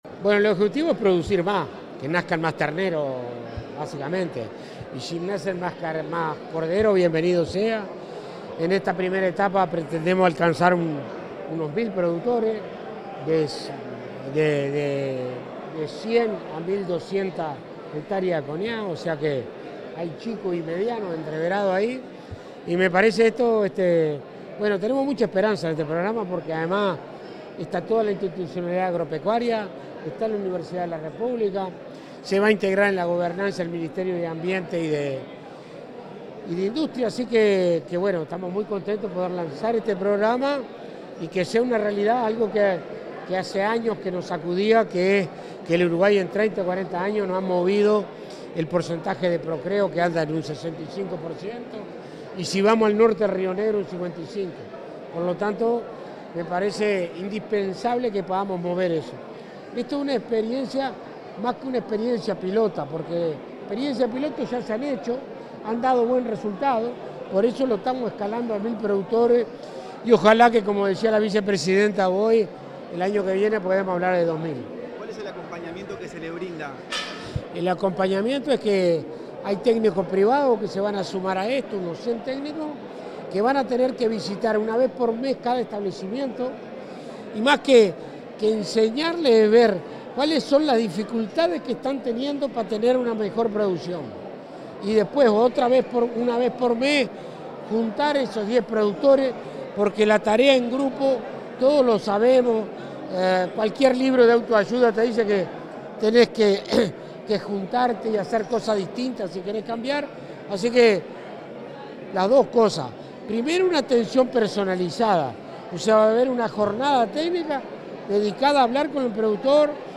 Declaraciones del ministro Alfredo Fratti en el lanzamiento del programa Procría
Declaraciones del ministro Alfredo Fratti en el lanzamiento del programa Procría 02/07/2025 Compartir Facebook X Copiar enlace WhatsApp LinkedIn El ministro de Ganadería, Agricultura y Pesca, Alfredo Fratti, realizó declaraciones a la prensa en la presentación del Programa de Innovación para una Ganadería de Cría Sostenible (Procría).